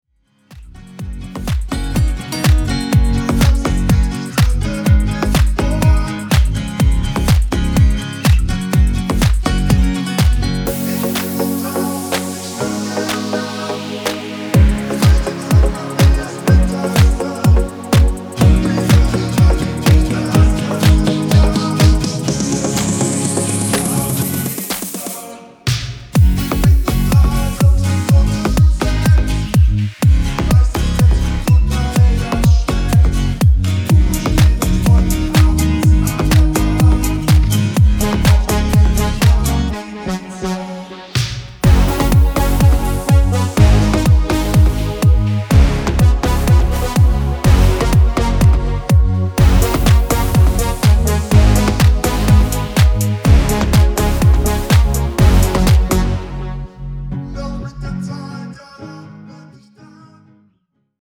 Demo in Gb